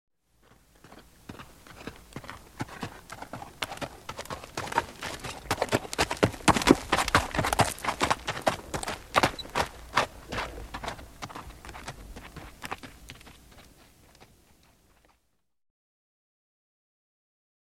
دانلود آهنگ اسب 82 از افکت صوتی انسان و موجودات زنده
دانلود صدای اسب 82 از ساعد نیوز با لینک مستقیم و کیفیت بالا
جلوه های صوتی